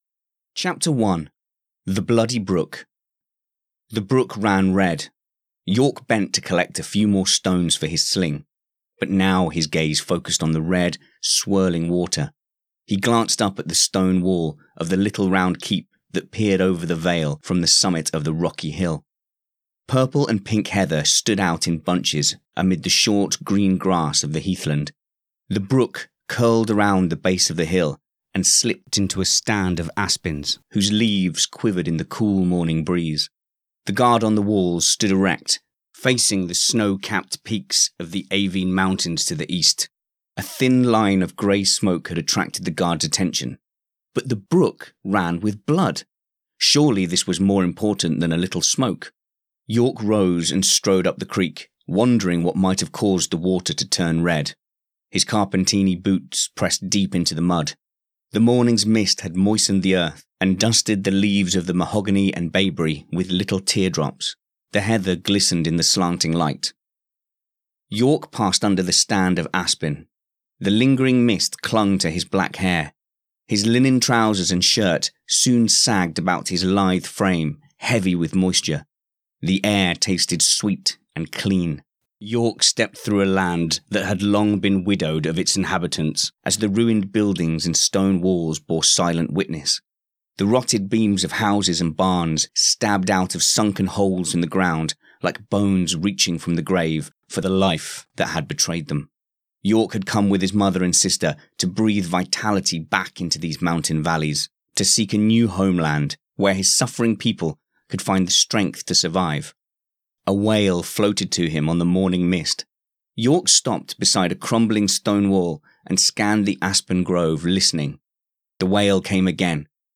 Audio Book Sample Chapter One The Bloody Brook